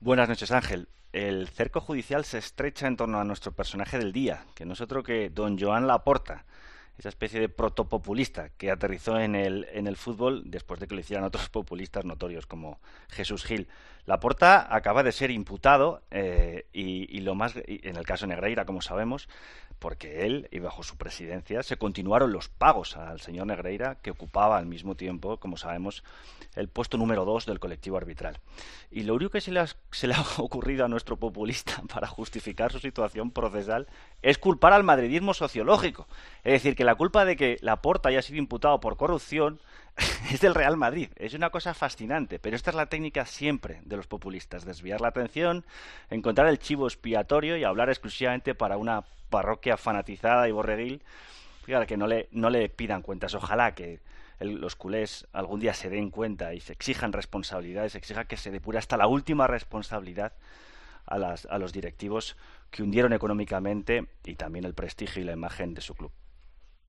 El colaborador dedica su editorial al presidente del FC Barcelona después de que este acusase al madridismo sociológico de que haya sido imputado
Escucha la respuesta de Jorge Bustos en La Linterna a las excusas de Laporta tras su imputación